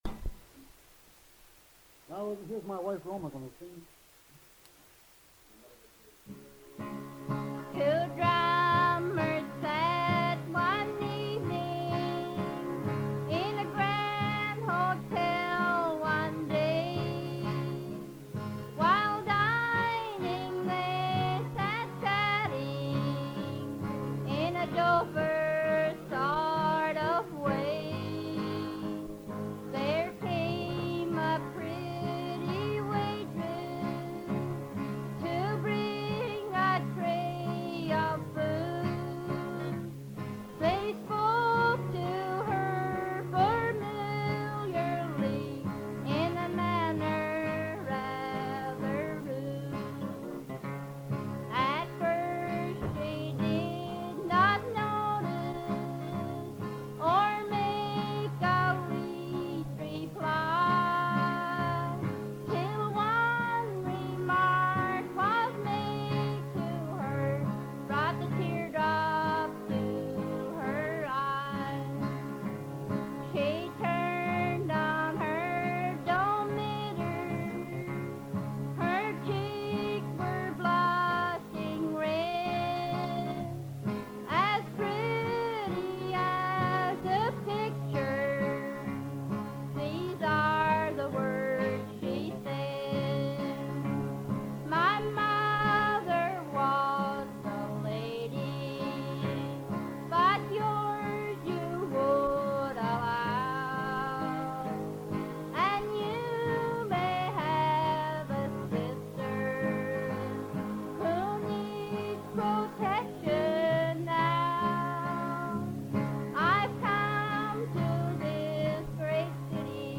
an old ballad
had the kind of strong, clear voice essential for conveying the story and keeping your attention as things unfold.
guitar